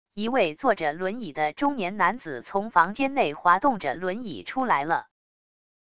一位坐着轮椅的中年男子从房间内滑动着轮椅出来了wav音频